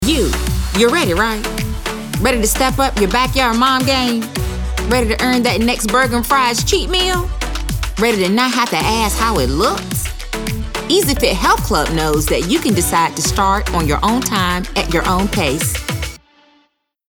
African American, announcer, confident, friendly, high-energy, middle-age, perky, retail